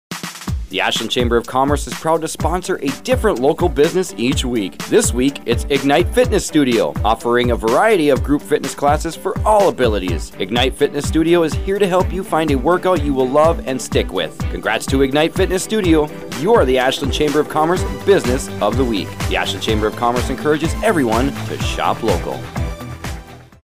Each week the Ashland Area Chamber of Commerce highlights a business on Heartland Communications radio station WATW 1400AM. The Chamber draws a name from our membership and the radio station writes a 30-second ad exclusively for that business.